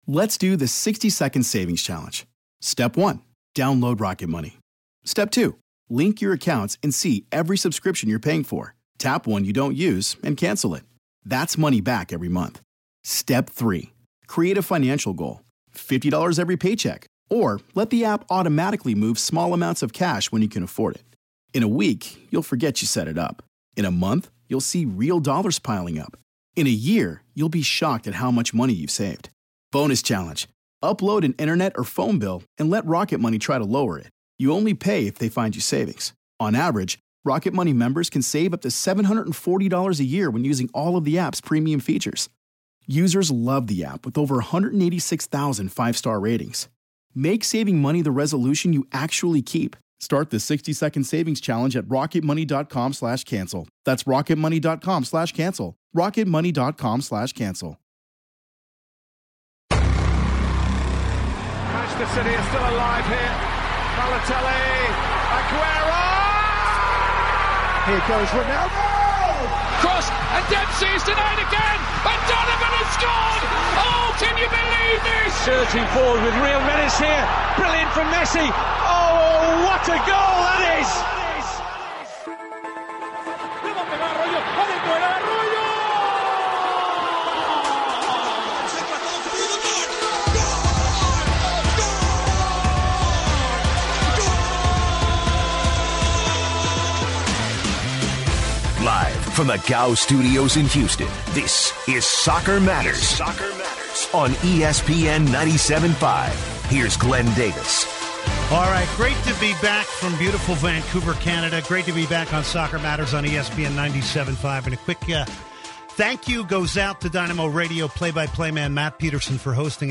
Plus callers and more!